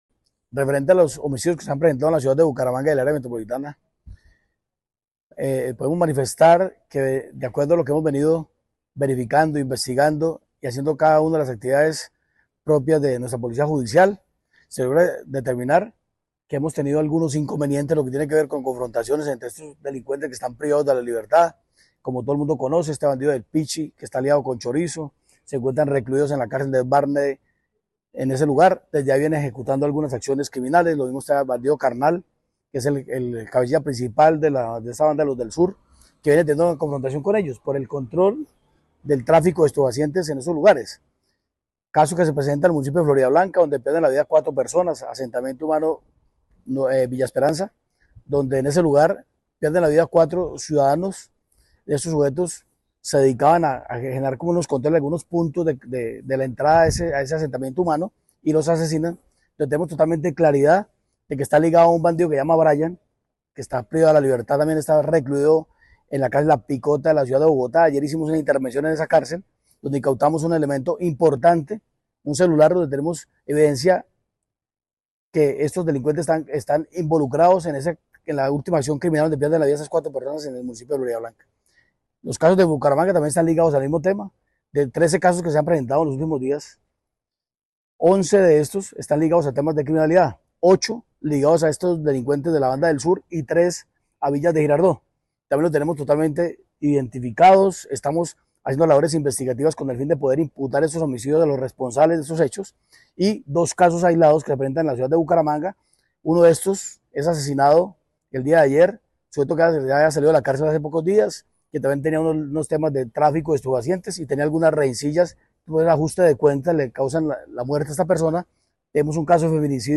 General William Quintero, comandante de la Policía Metropolitana de Bucaramanga